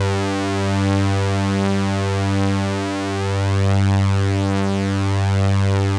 G3_trance_lead_1.wav